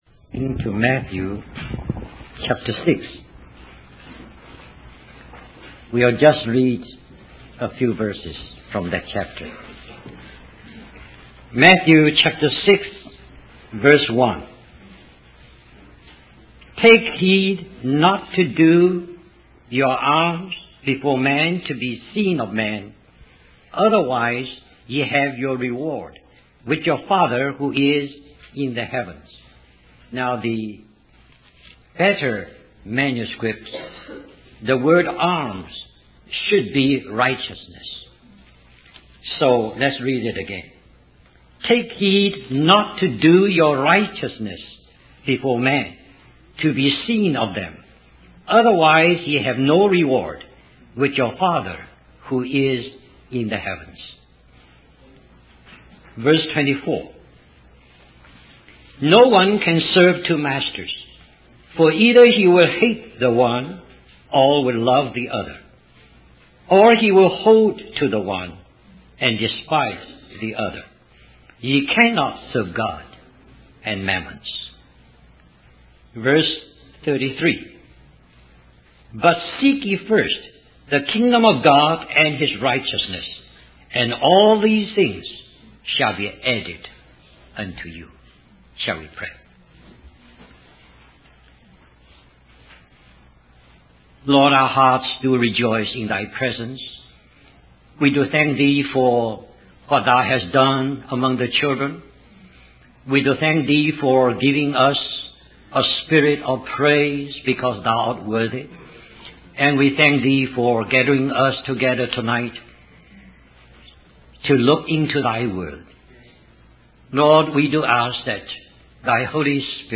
In this sermon, the preacher discusses the difference in attitude towards eating, drinking, and dressing between believers and the world.